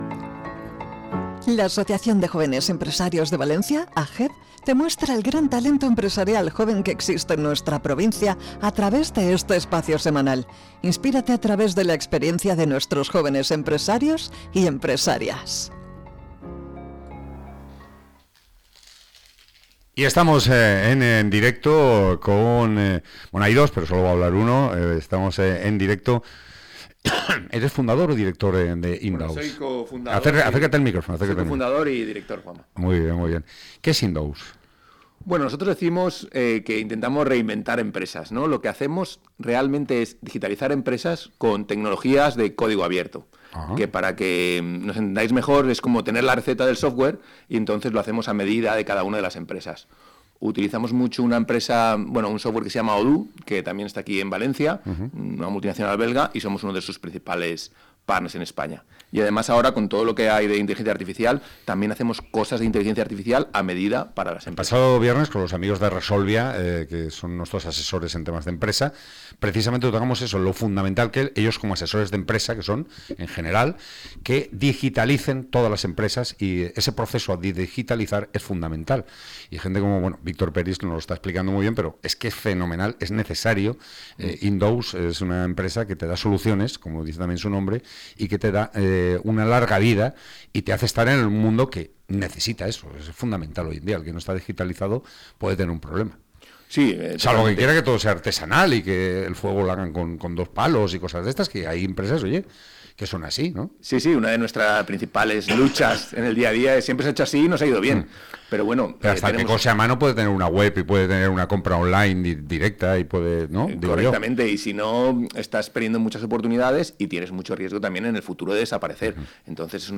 Entrevista
En su paso por el espacio de AJEV en Valencia Radio, compartió cómo su empresa ayuda a compañías de todos los tamaños a adaptarse al entorno digital para garantizar su sostenibilidad.